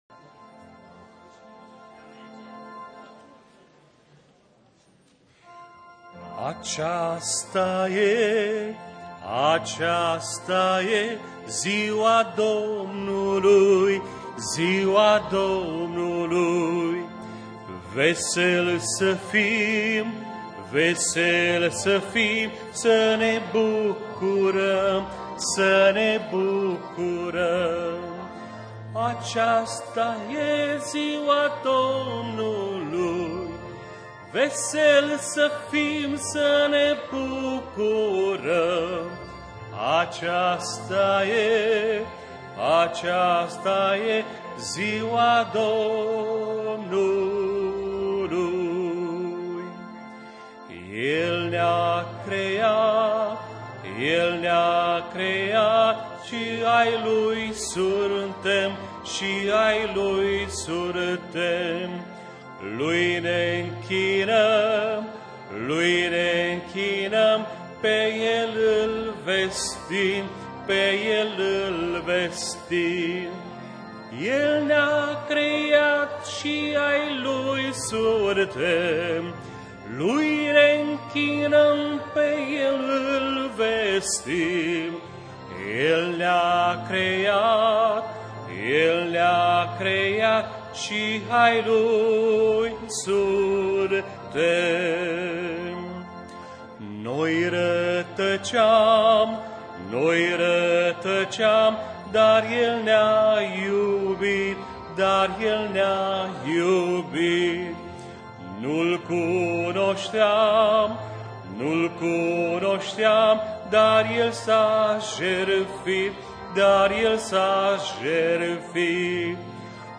Timisoara » July 24, 2005 09:00 Place: Timisoara - Cinema Timis, Piata Victoriei no 7, Romania Cinema Timis address: Piata Victoriei no 7 tel.